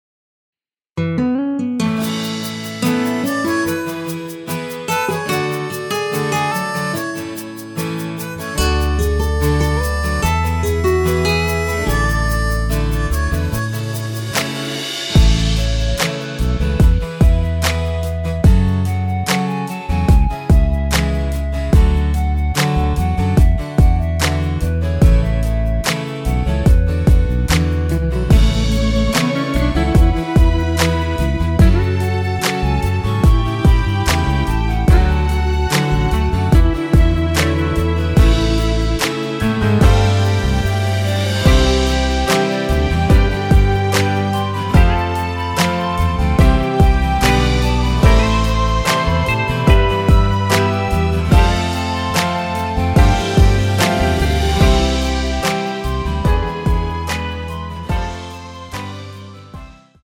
노래방에서 노래를 부르실때 노래 부분에 가이드 멜로디가 따라 나와서
앞부분30초, 뒷부분30초씩 편집해서 올려 드리고 있습니다.
중간에 음이 끈어지고 다시 나오는 이유는